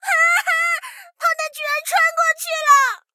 SU-76小破语音2.OGG